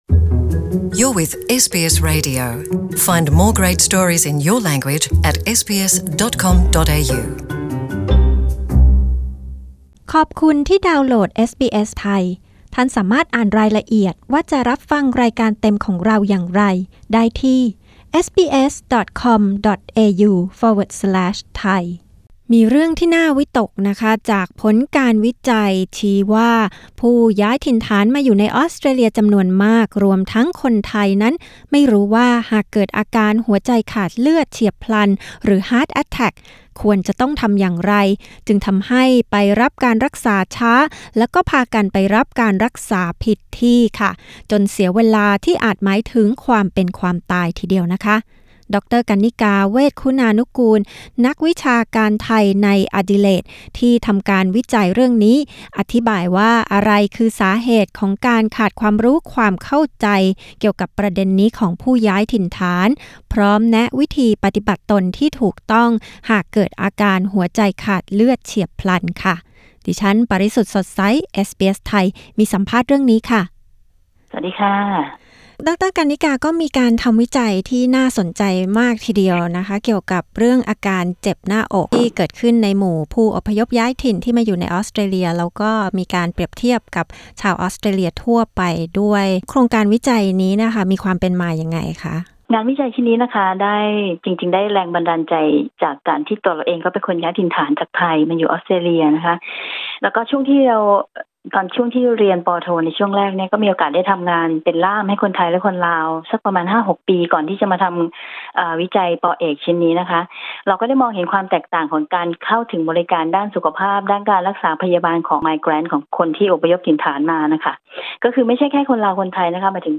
กดปุ่ม 🔊 ด้านบนเพื่อฟังสัมภาษณ์เรื่องนี้